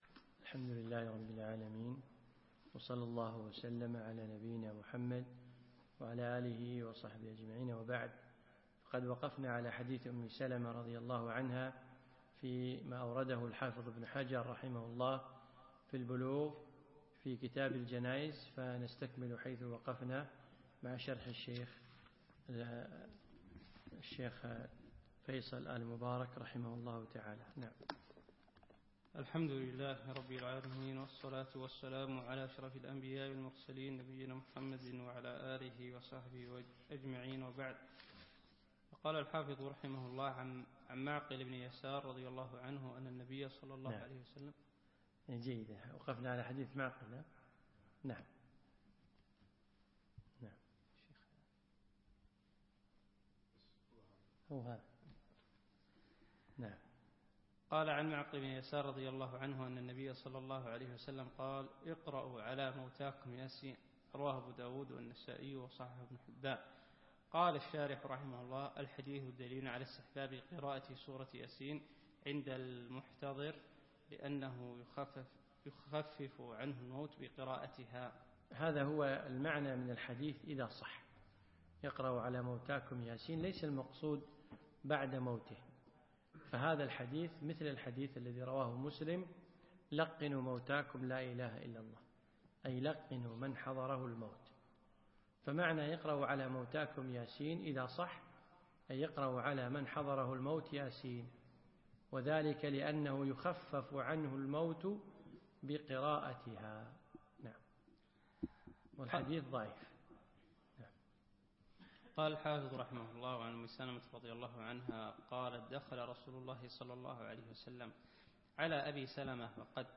الدرس العشرون